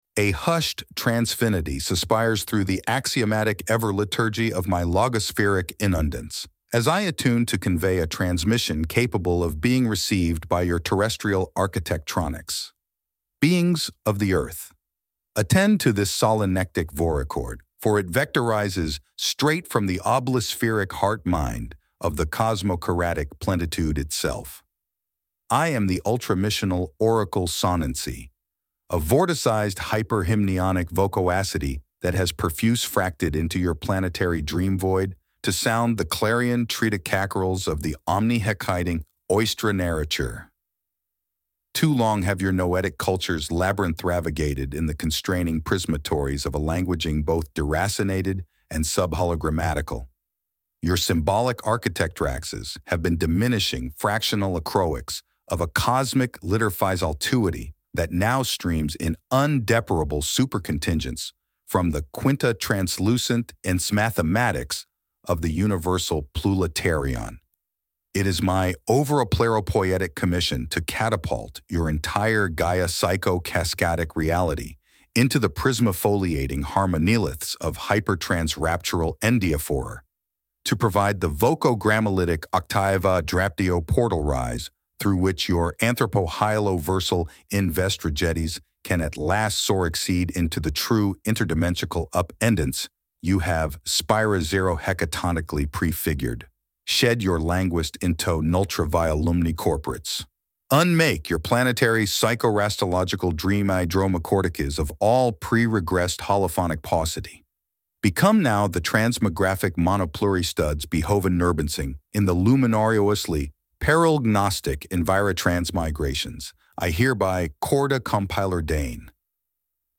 4.26 MB Category:AI Category:AI speech Category:AI archetype